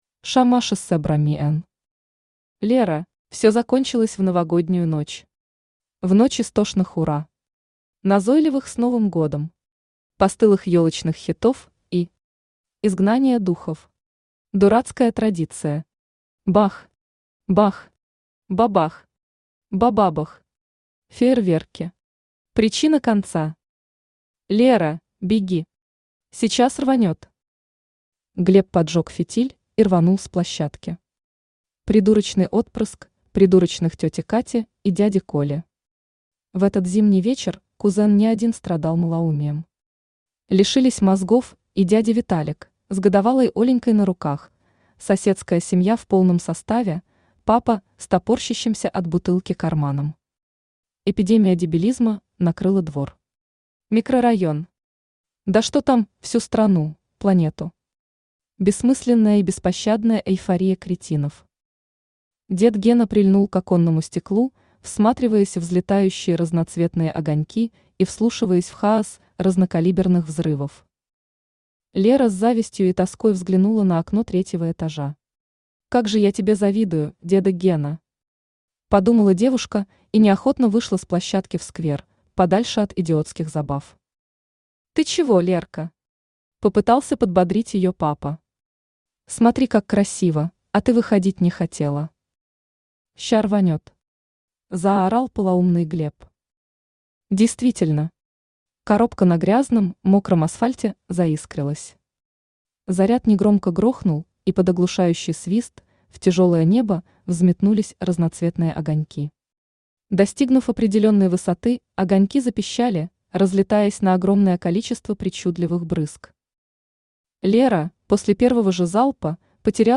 Aудиокнига Лера Автор ШаМаШ БраМиН Читает аудиокнигу Авточтец ЛитРес.